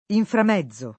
inframmezzare v.; inframmezzo [ inframm $zz o ]